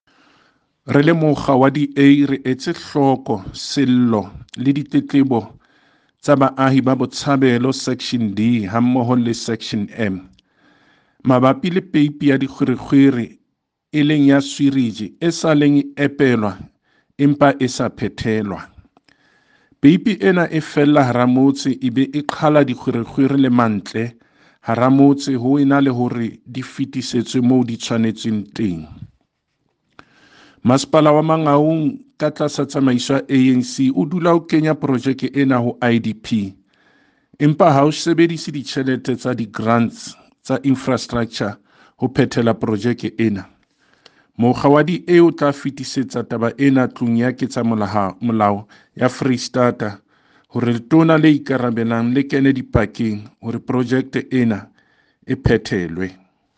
Sesotho soundbites by Cllr David Masoeu.